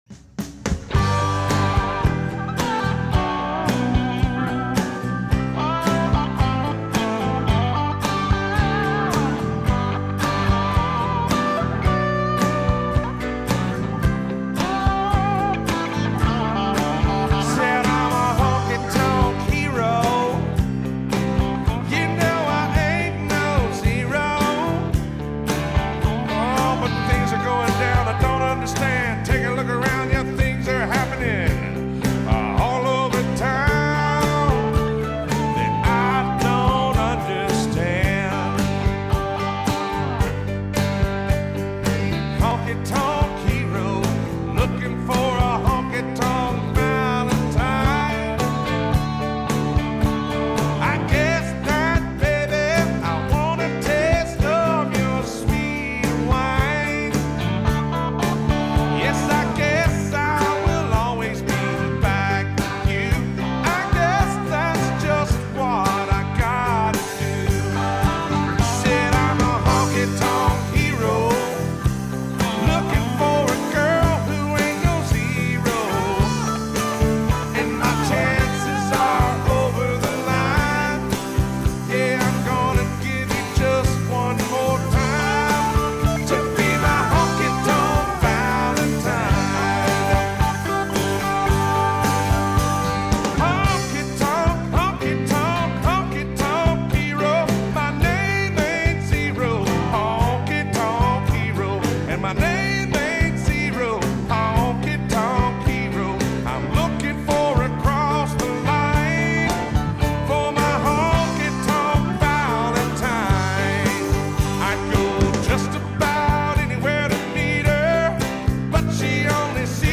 Country song about a honkytonk hanger oner